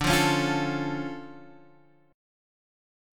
DmM7 chord